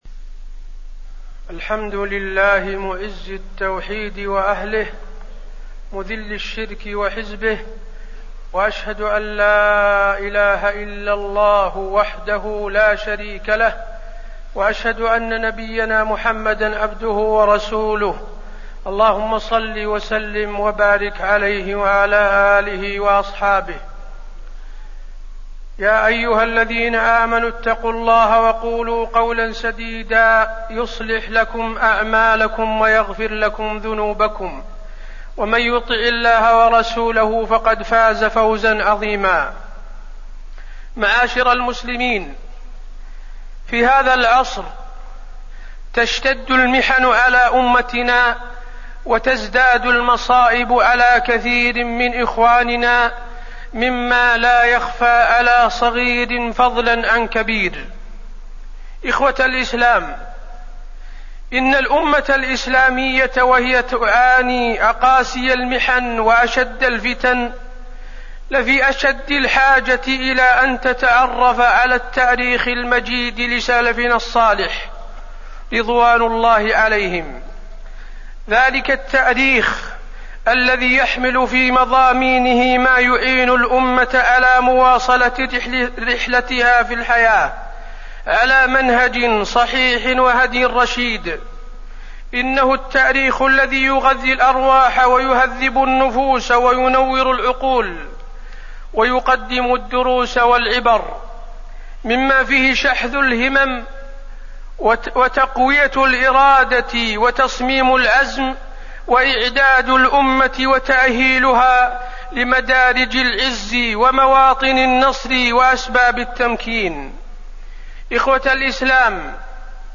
تاريخ النشر ٢ جمادى الأولى ١٤٣١ هـ المكان: المسجد النبوي الشيخ: فضيلة الشيخ د. حسين بن عبدالعزيز آل الشيخ فضيلة الشيخ د. حسين بن عبدالعزيز آل الشيخ تاريخ الصحابة